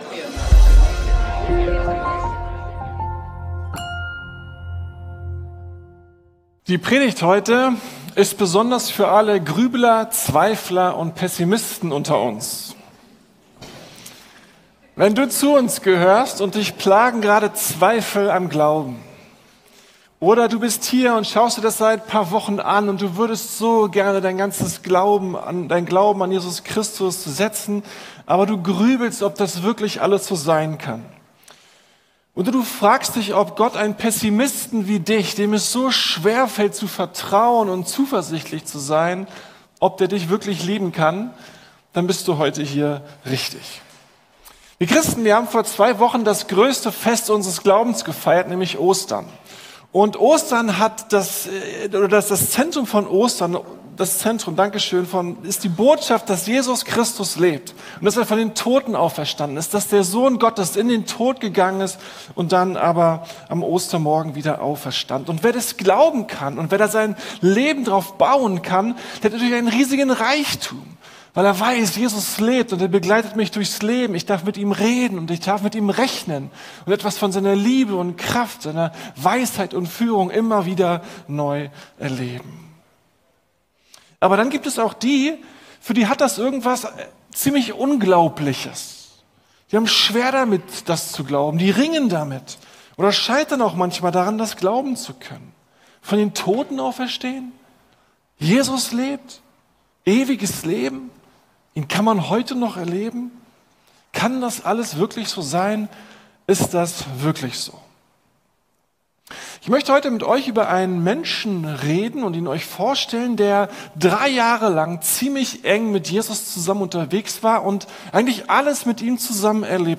Predigten der LUKAS GEMEINDE